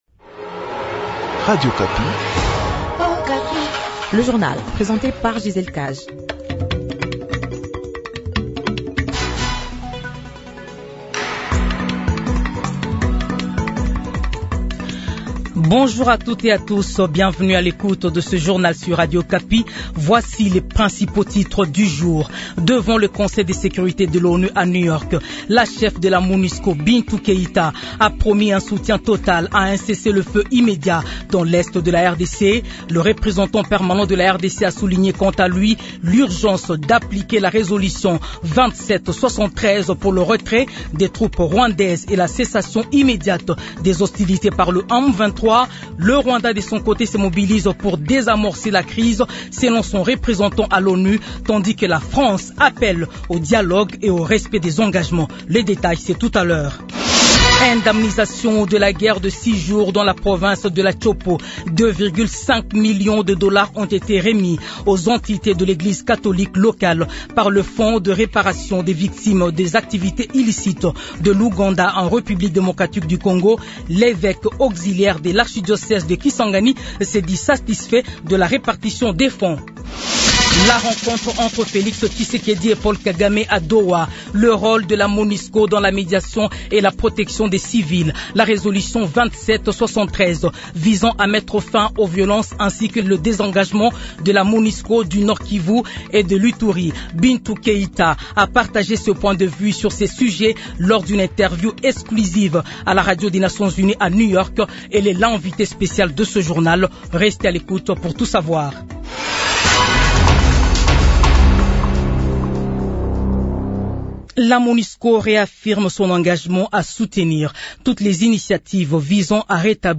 Journal 8h